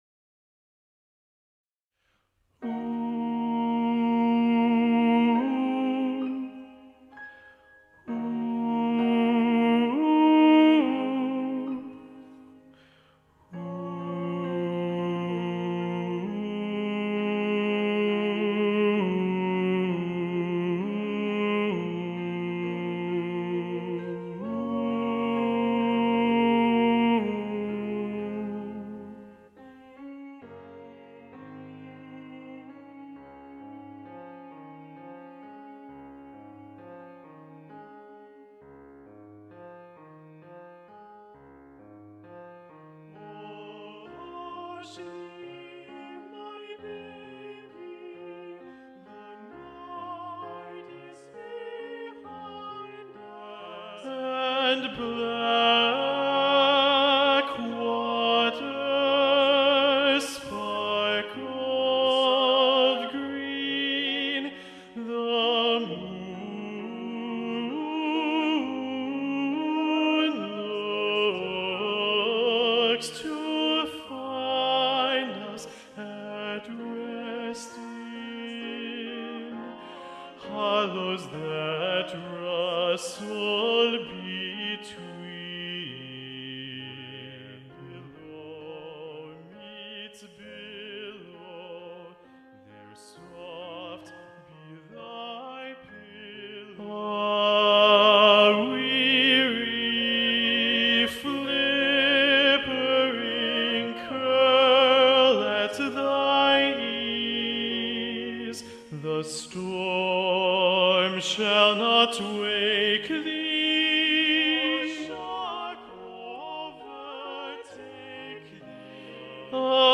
Seal_Lullaby_Tenor.mp3